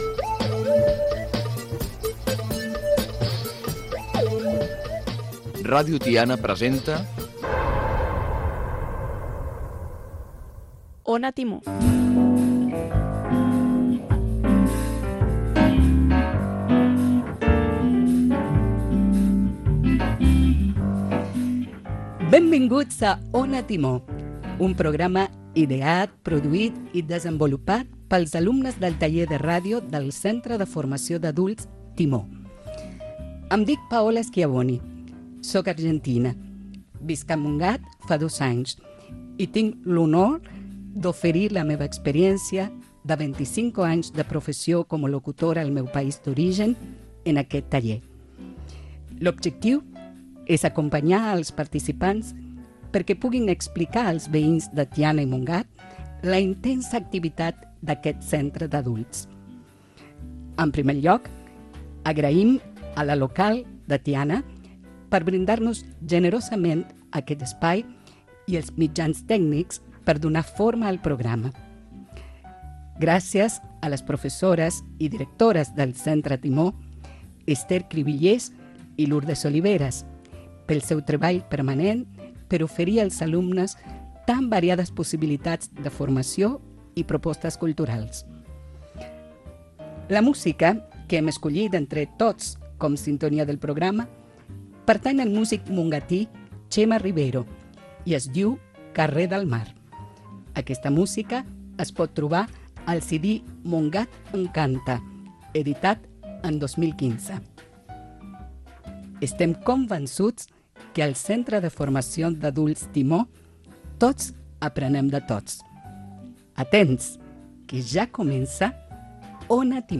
Careta del programa, presentació, autor de la sintonia del programa, activitats del centre Timó.
Espai realitzat pels alumnes del taller de ràdio i teatre de l’Escola d’Adults Timó.